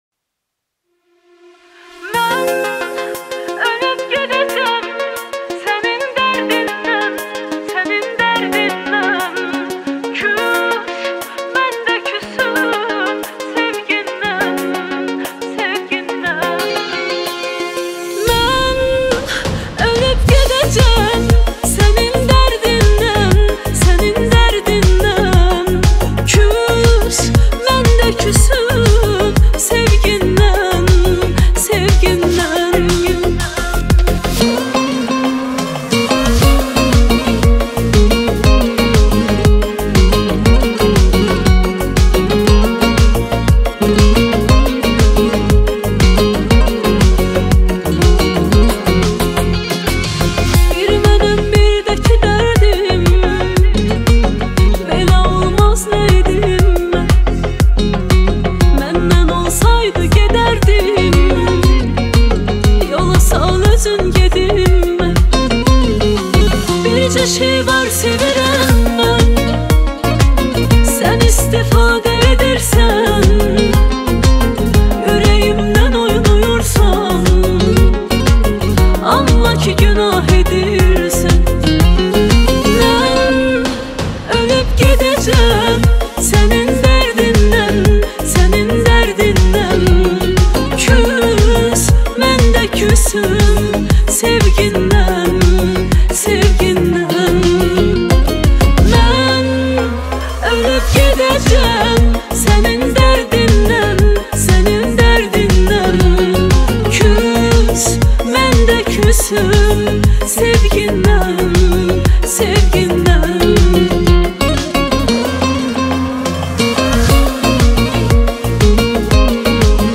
موزیک آذربایجانی